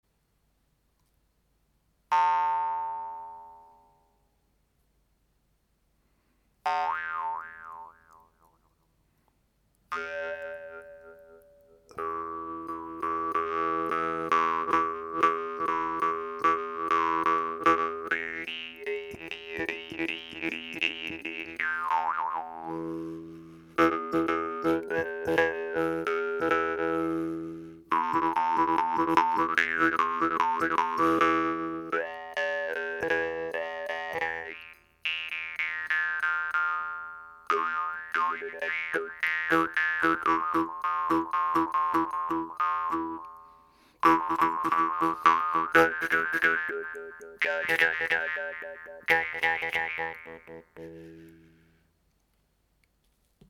Le cadre forgé de cette guimbarde est très solide, très rassurant, la languette agréable à activer, le son peut être vraiment puissant.